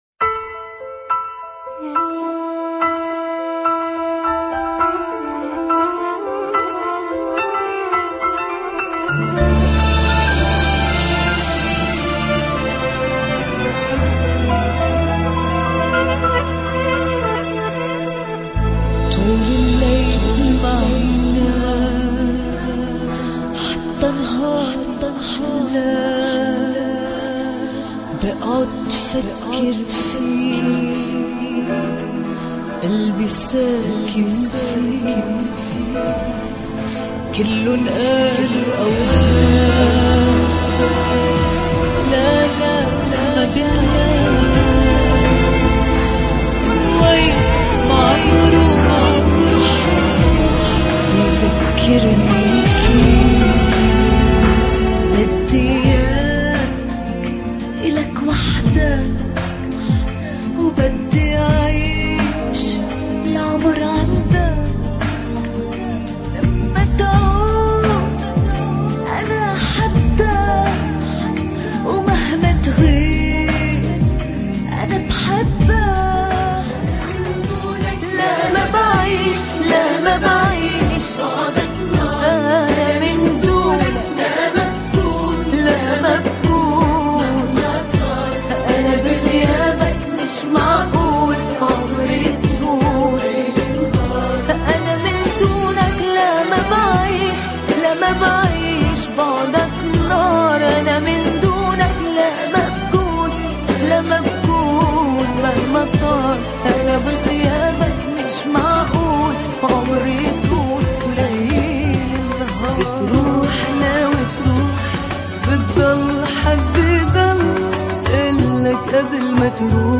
ميكس